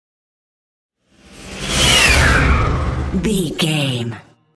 Airy whoosh large
Sound Effects
futuristic
whoosh
sci fi